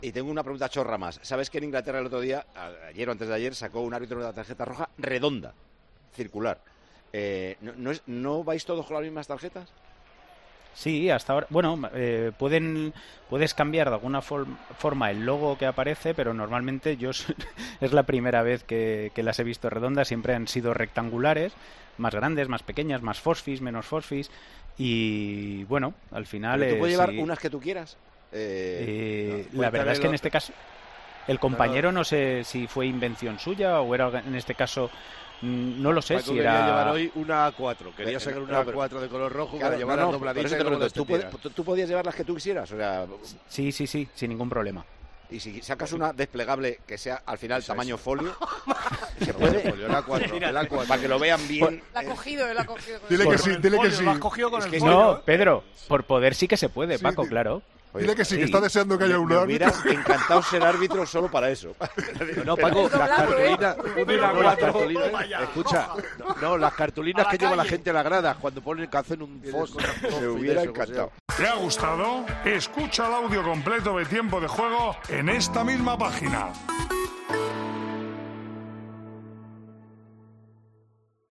La emoción del torneo copero se vivió en Tiempo de Juego con Paco González desde las 15:00h y antes del comienzo del partido entre Arandina y Real Madrid, que arrancó a las 21:30h, el director y presentador del programa líder de la radio deportiva española del fin de semana charló con nuestro especialista arbitral, el excolegiado internacional Mateu Lahoz, y durante esa conversación Paco González confesó la única razón por la que hubiera sido árbitro.